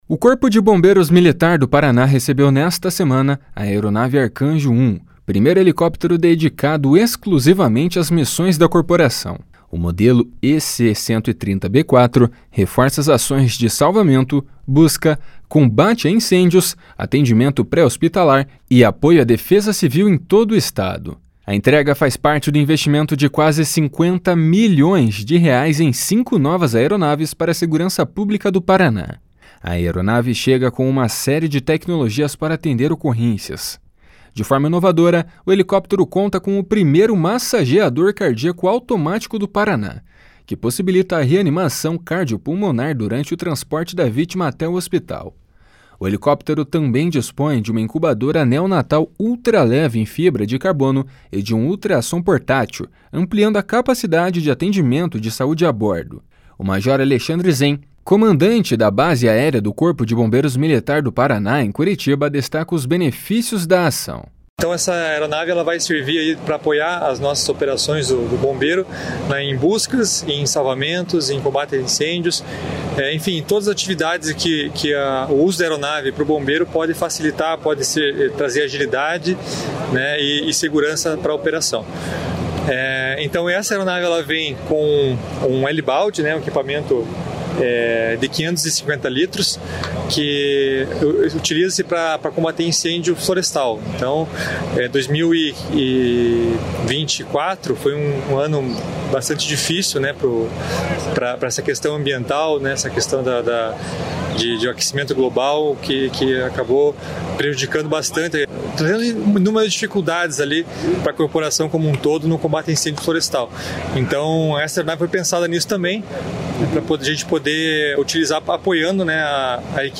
O Governo do Estado fez nesta terça-feira a maior ampliação da história da frota aérea da segurança pública do Paraná, com a entrega de cinco novos helicópteros. (Repórter: